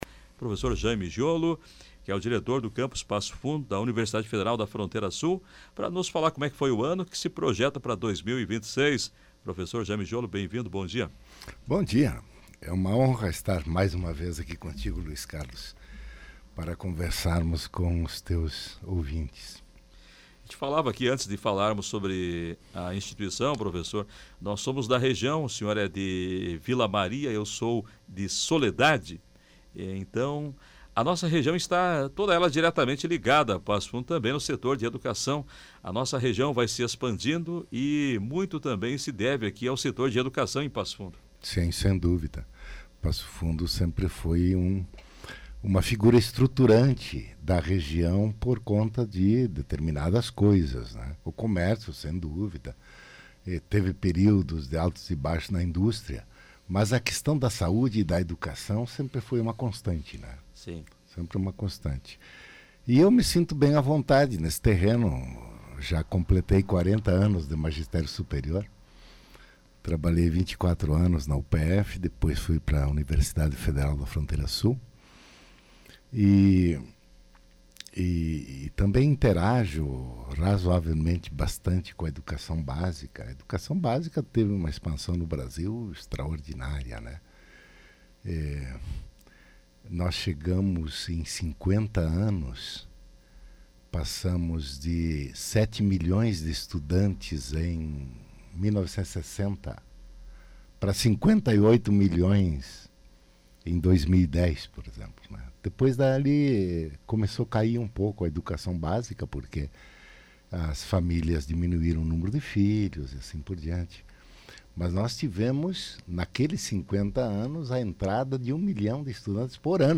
Entrevista: UFFS teve ano marcado pela chegada da Enfermagem e novidades virão em 2026